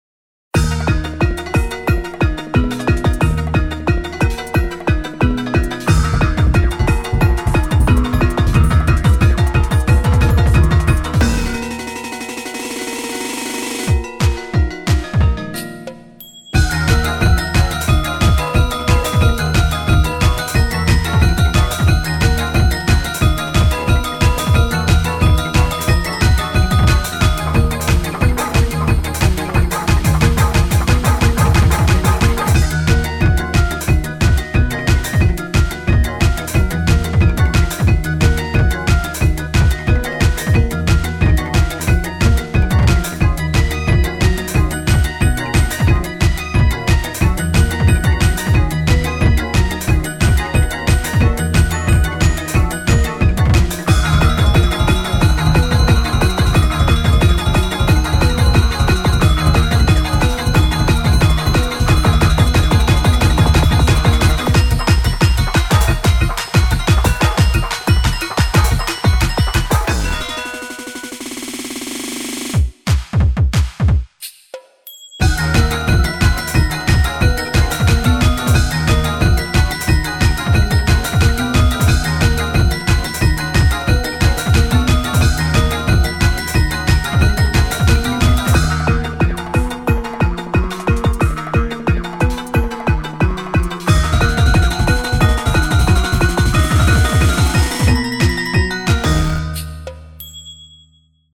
BPM180
Audio QualityPerfect (Low Quality)